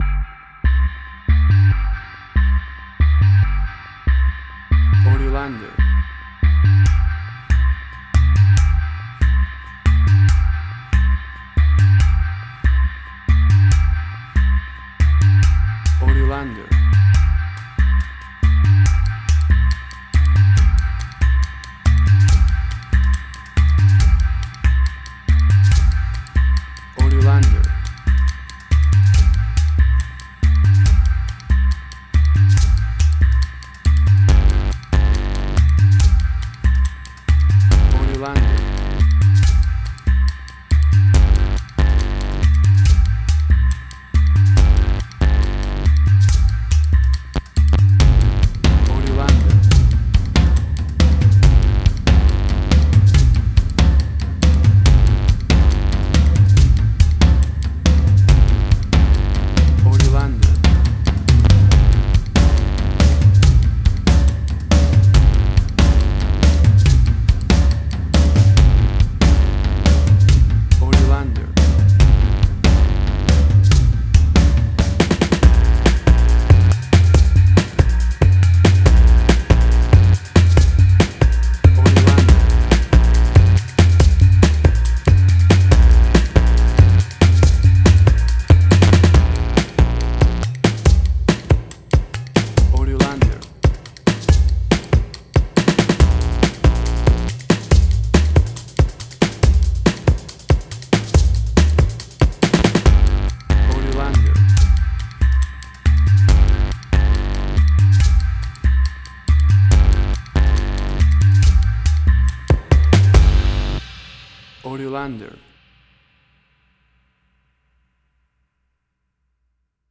Suspense, Drama, Quirky, Emotional.
WAV Sample Rate: 16-Bit stereo, 44.1 kHz
Tempo (BPM): 140